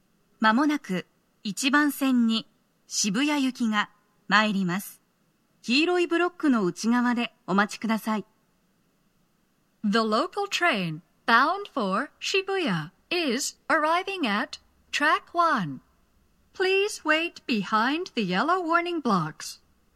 スピーカー種類 TOA天井型
鳴動は、やや遅めです。
接近放送 【女声